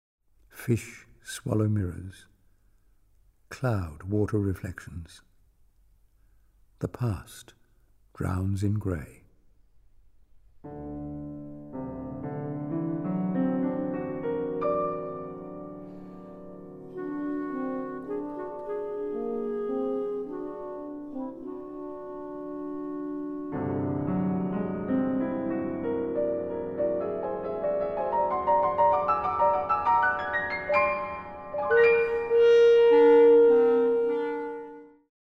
richly expressive woodwind palette